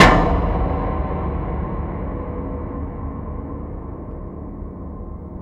SI2 PIANO0JL.wav